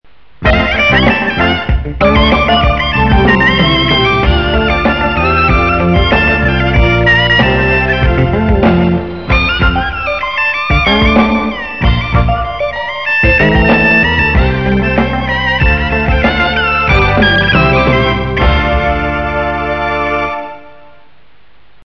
0036-京胡名曲西皮加花小开门.mp3